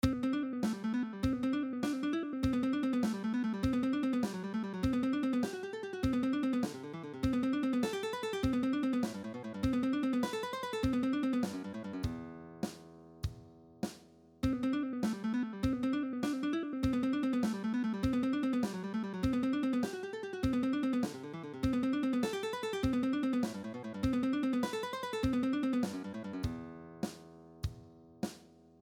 A big lick with full of skippings.
An-Organized-String-Skipping-Lick-1.mp3